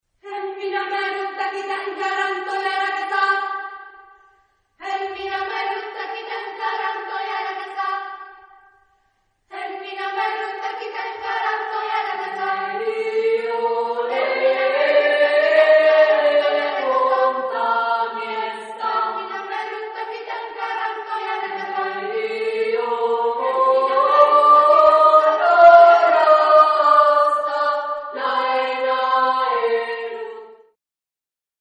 Género/Estilo/Forma: Profano
Carácter de la pieza : intenso
Tipo de formación coral: SSAA  (4 voces Coro infantil )
Tonalidad : sol menor